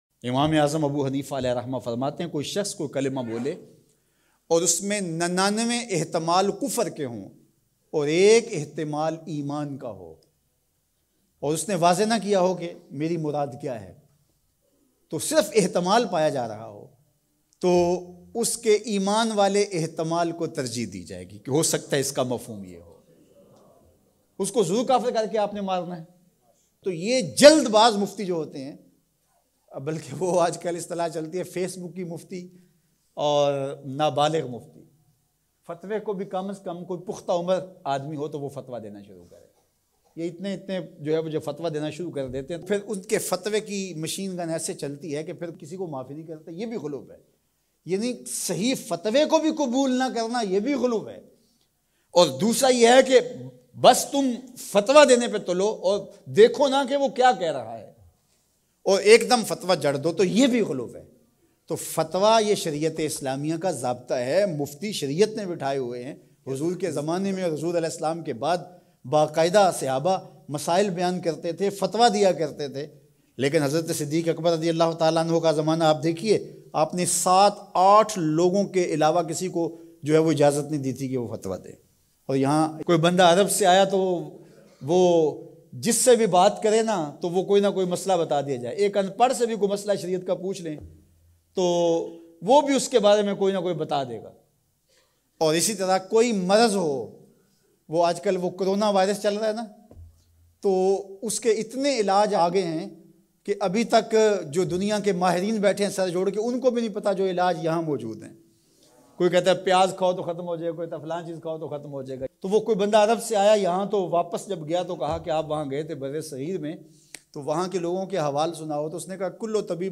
Bayan-About-Corona-Virus.mp3